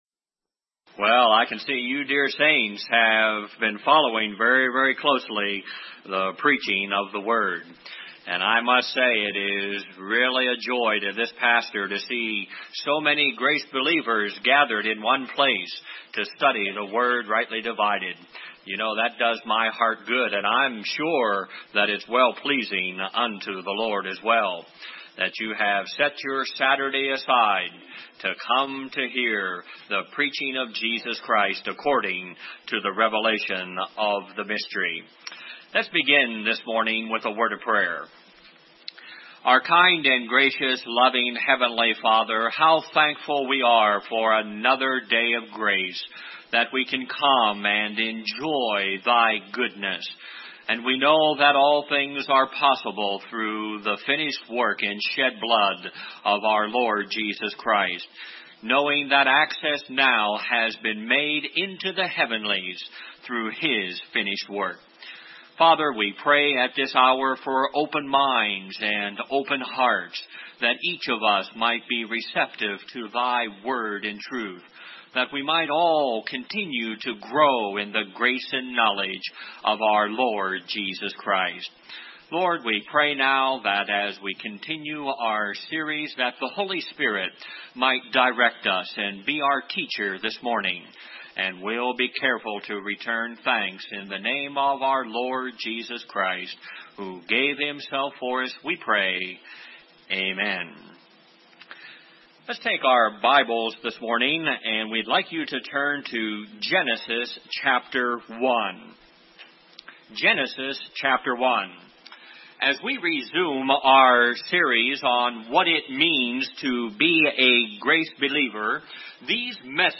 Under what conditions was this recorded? A message from the 1990 Spring Bible Conference of the Midwest Grace Fellowship.